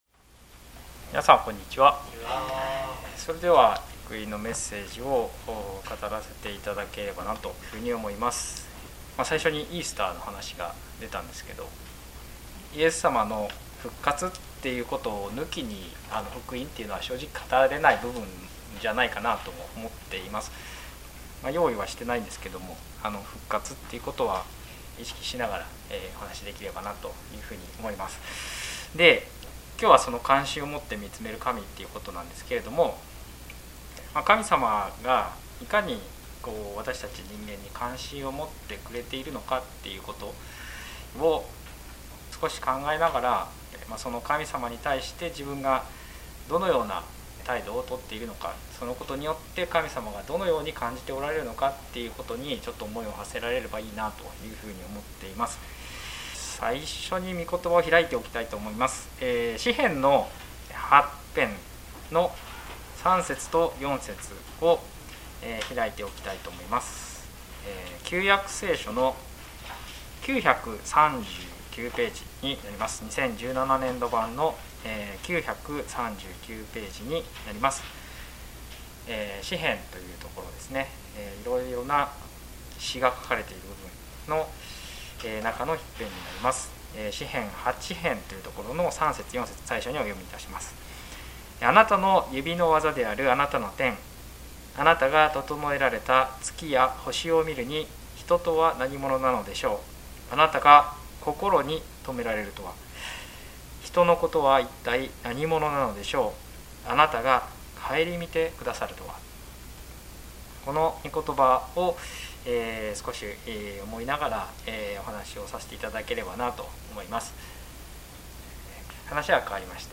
聖書メッセージ No.159